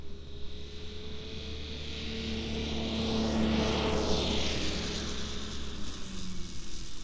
Compression Ignition Snowmobile Description Form (PDF)
Compression Ignition Subjective Noise Event Audio File (WAV)